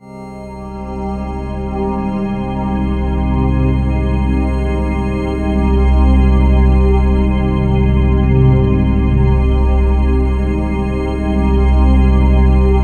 Index of /90_sSampleCDs/Infinite Sound - Ambient Atmospheres/Partition A/04-BEAUTY B